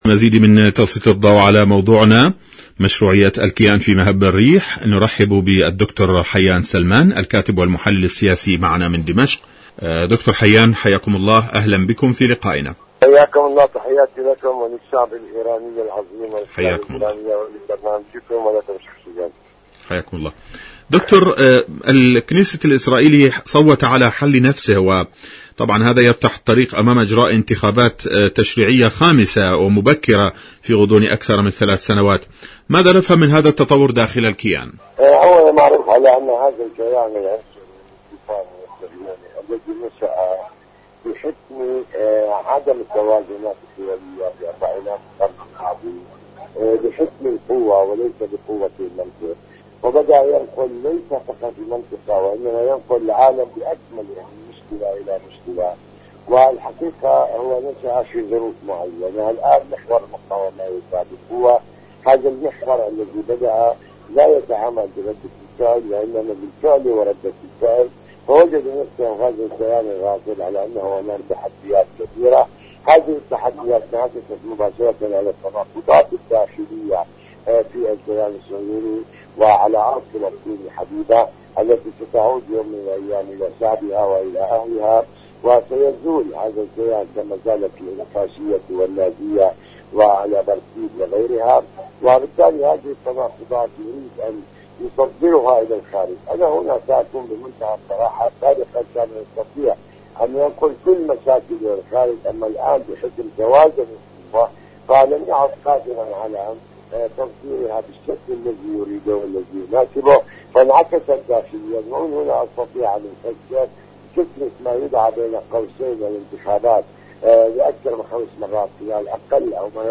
مقابلات برامج إذاعة طهران العربية برنامج حدث وحوار مقابلات إذاعية الكيان الصهيوني المشروعية مهب الريح حدث وحوار شاركوا هذا الخبر مع أصدقائكم ذات صلة دور العلاج الطبيعي بعد العمليات الجراحية..